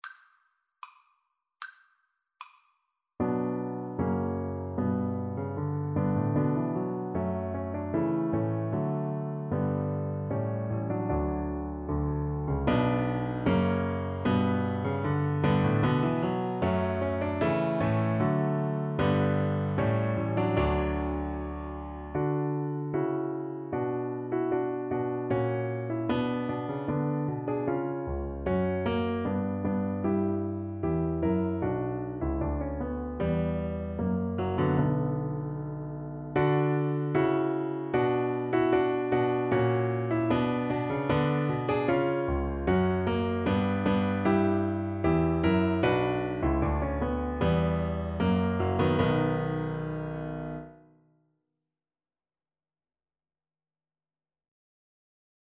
Play (or use space bar on your keyboard) Pause Music Playalong - Piano Accompaniment Playalong Band Accompaniment not yet available transpose reset tempo print settings full screen
B minor (Sounding Pitch) (View more B minor Music for Cello )
Steadily =c.76
Classical (View more Classical Cello Music)